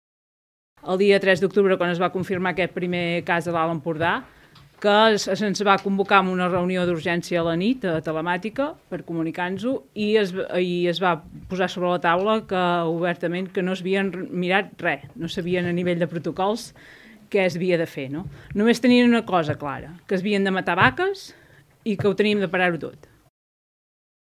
En una roda de premsa celebrada a Girona, Unió de Pagesos ha reclamat intensificar la campanya de vacunació del bestiar i ha proposat ampliar el nombre de professionals veterinaris a les explotacions per garantir una vacunació efectiva i àmplia.